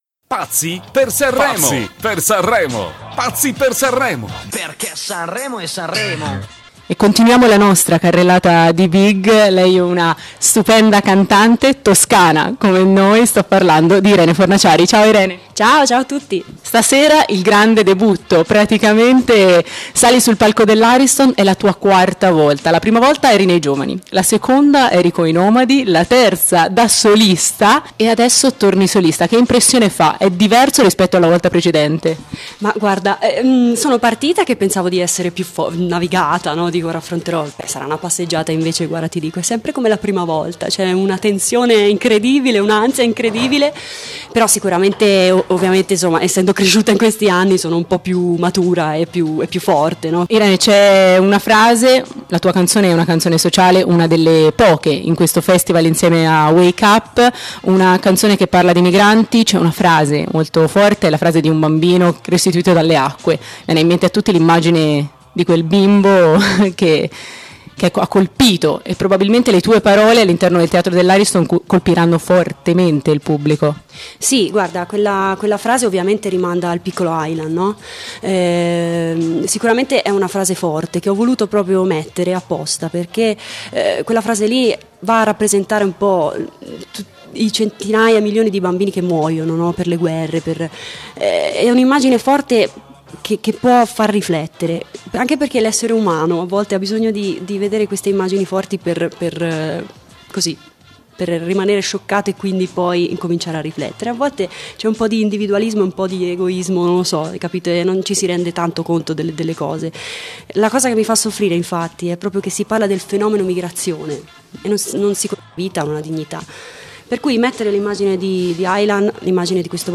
Are Speciale Sanremo: l'intervista a Irene Fornaciari - Antenna Radio Esse